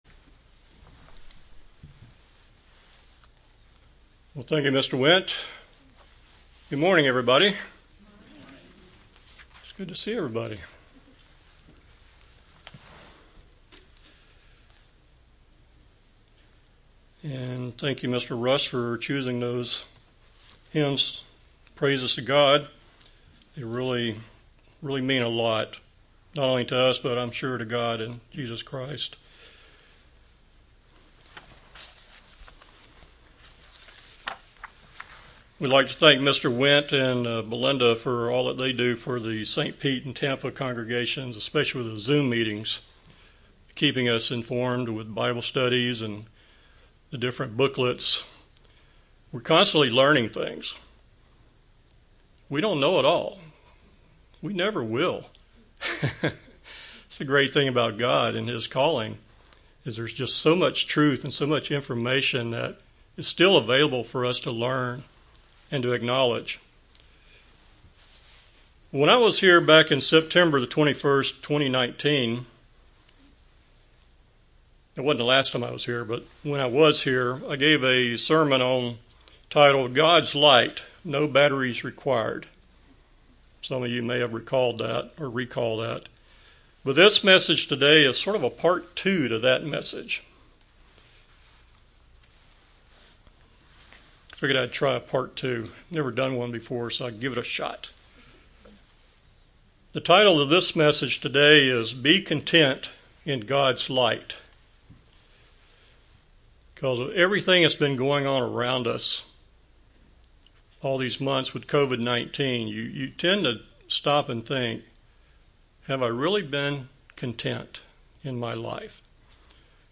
Sermons
Given in St. Petersburg, FL